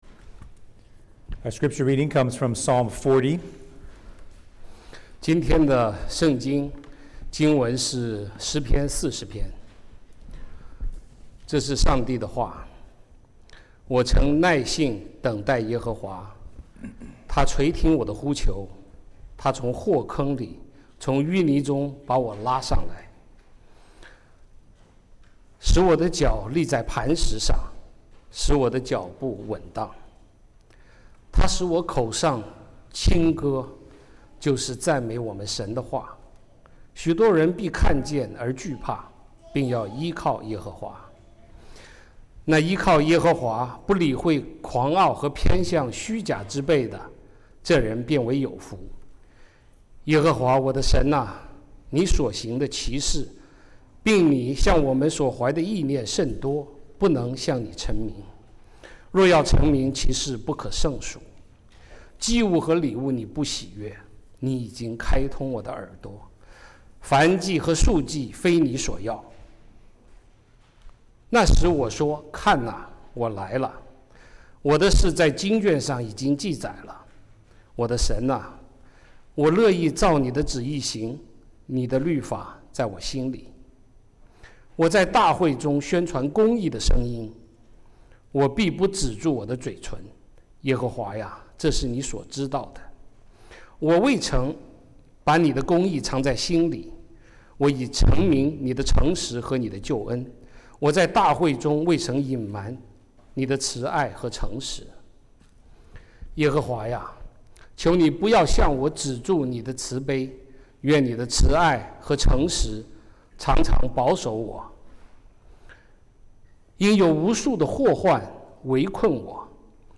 外来牧师讲道｜即将来临的弥赛亚（诗篇40）